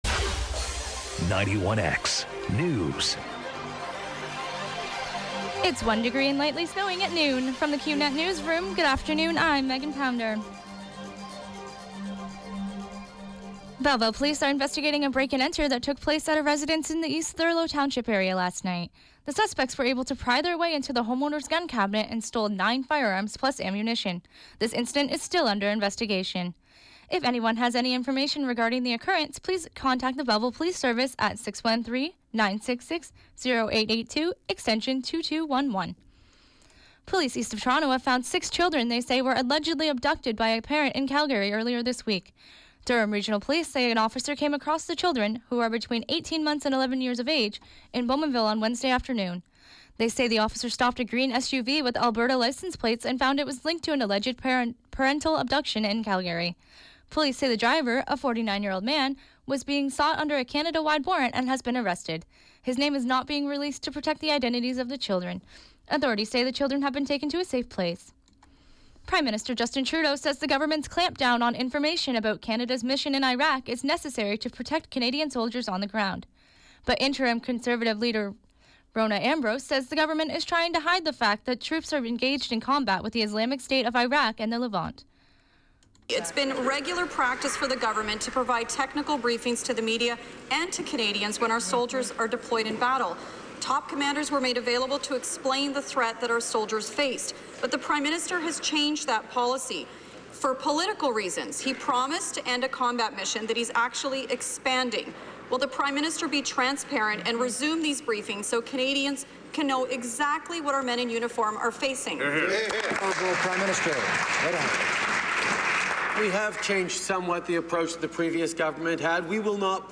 91X FM Newscast- Thursday, October 27, 2016, 12 p.m.